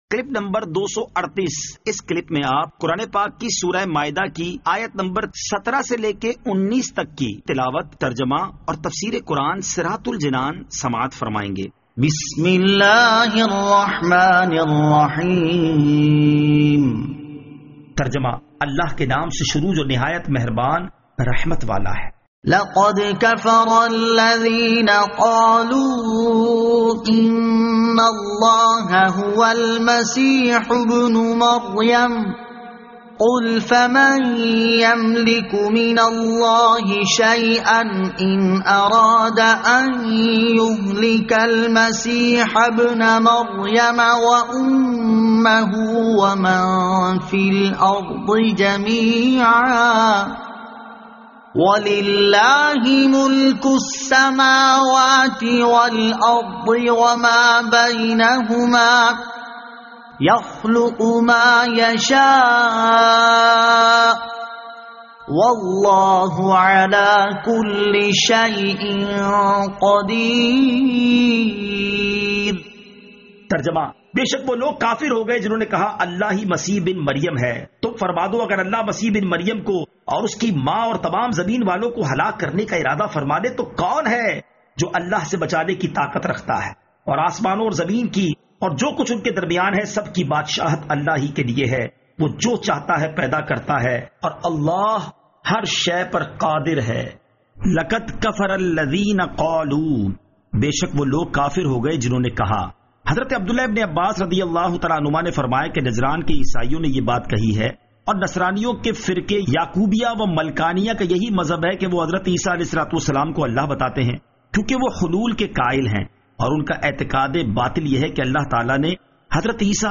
Surah Al-Maidah Ayat 17 To 19 Tilawat , Tarjama , Tafseer